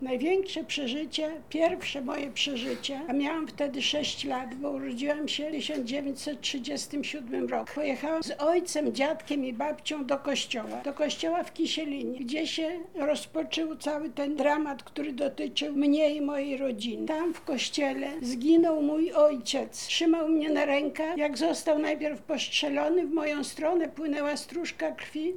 O działaniach poinformowano podczas konferencji prasowej zorganizowanej przez władze regionu.